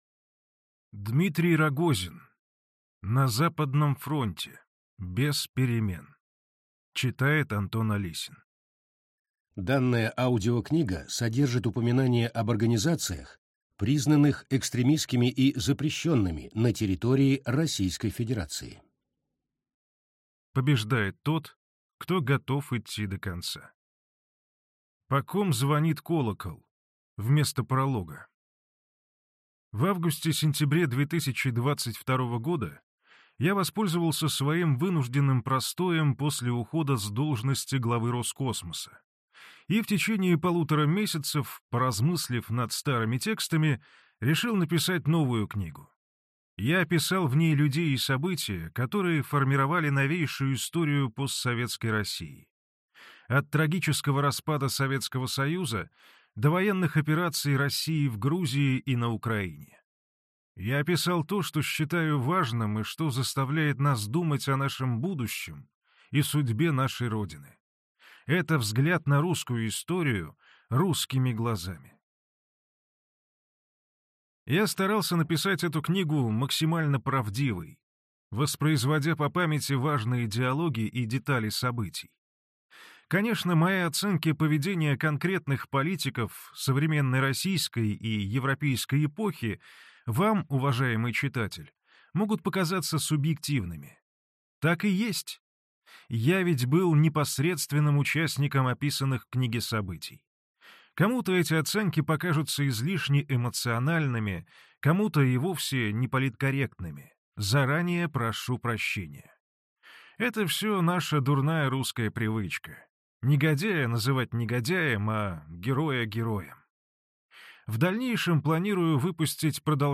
Аудиокнига На Западном фронте. Бес перемен | Библиотека аудиокниг